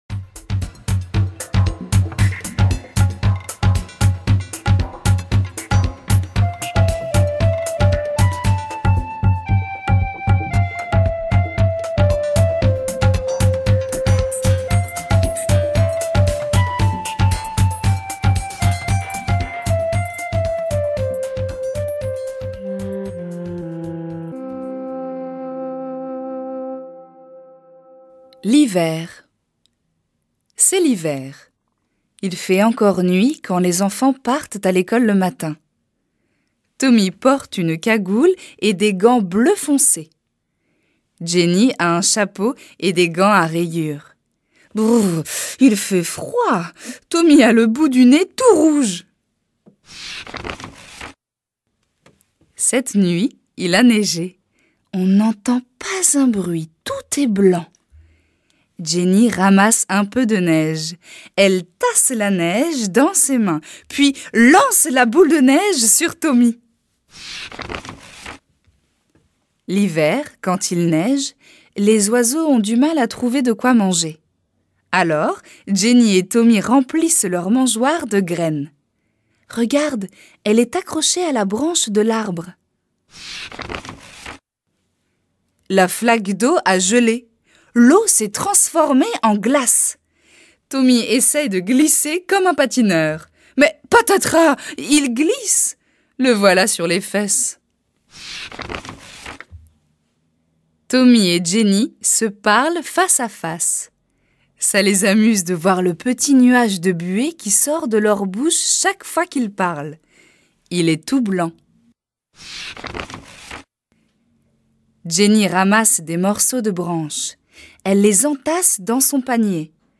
L’album est introduit en musique et un bruit de page que l’on tourne est reproduit à chaque fois que l’enfant doit tourner la page.
Les textes de « Throughout the seasons with Jenny and Tommy », série 3 des Petites Histoires Bilingues sont interprétés par des comédiennes professionnelles natives :
Extrait en français